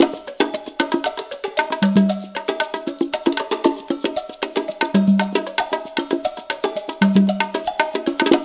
CONGA BEAT29.wav